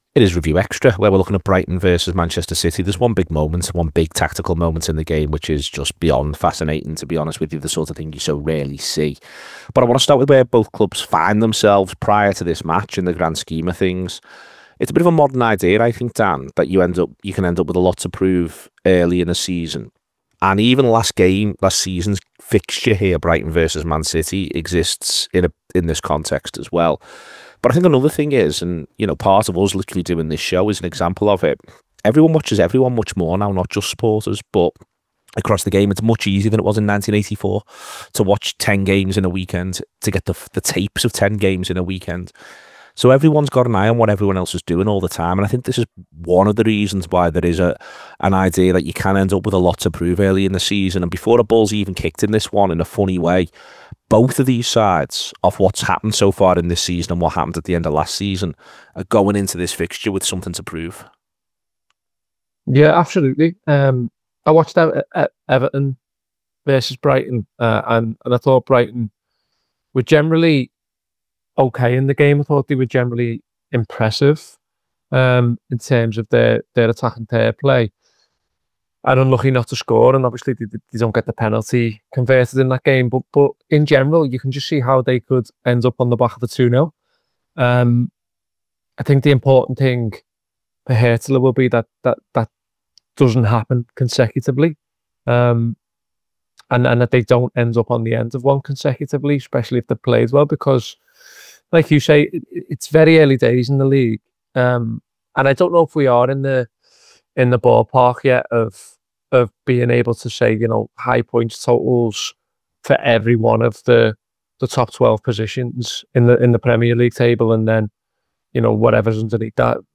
Below is a clip from the show – subscribe for more review chat around Brighton 2 Manchester City 1…